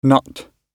nut-gb.mp3